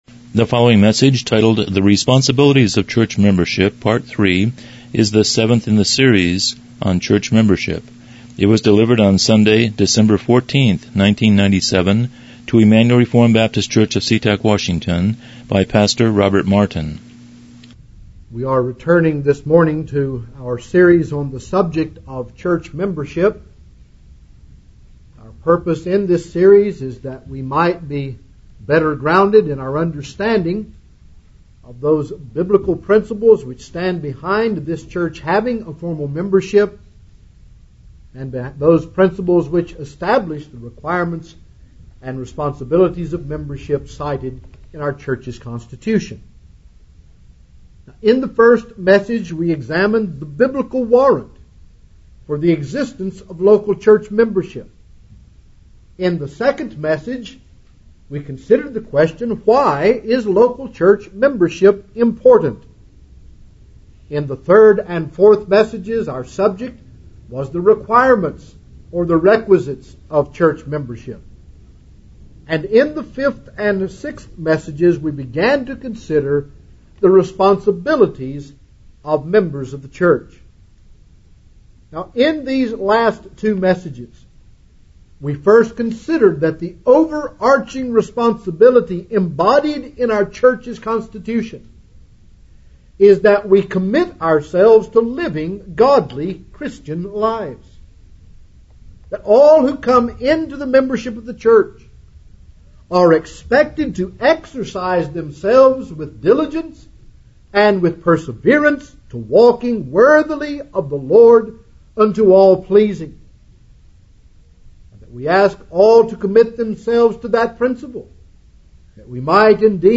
Church Membership Service Type: Morning Worship « 06 Responsibilities of